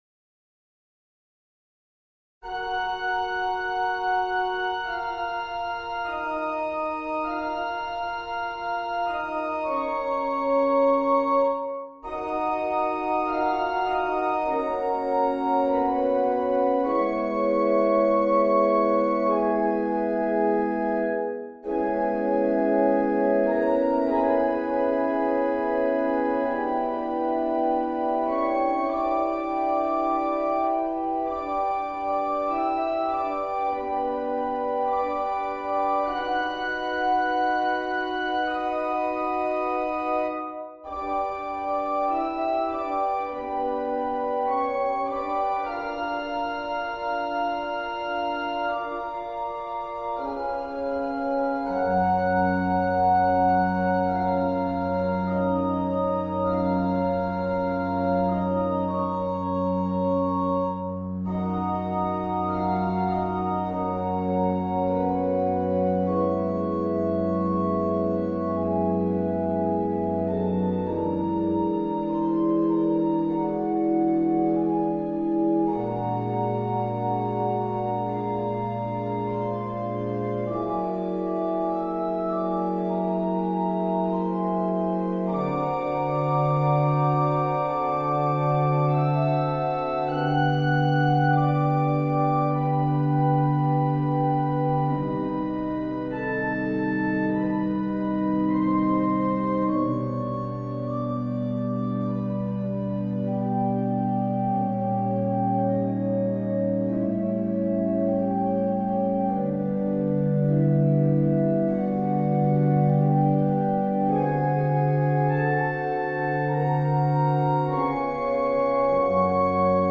Méditation en sol bémol majeur, op.4, n°1, pour orgue